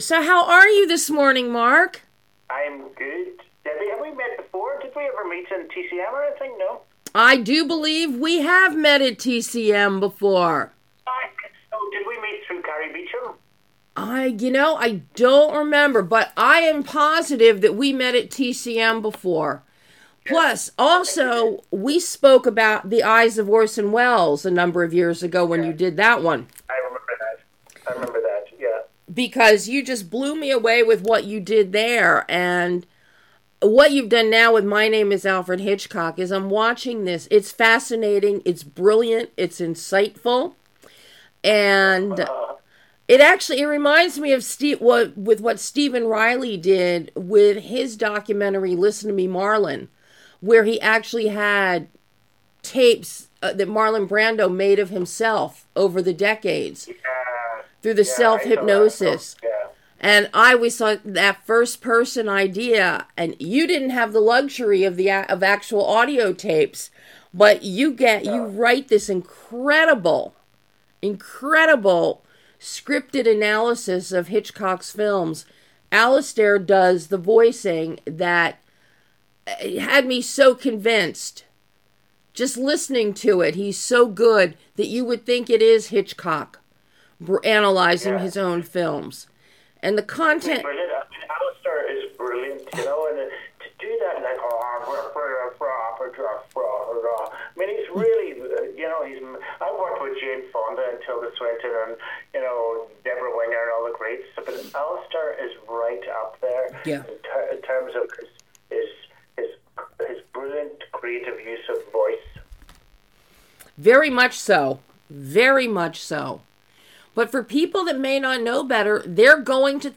A fascinating exclusive conversation with director MARK COUSINS discussing his mesmerizing analysis of the films of Hitchcock told in this very unique documentary, MY NAME IS ALFRED HITCHCOCK.